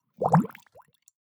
WATRBubl_WATER_Cute_Interface_Bubble_06.wav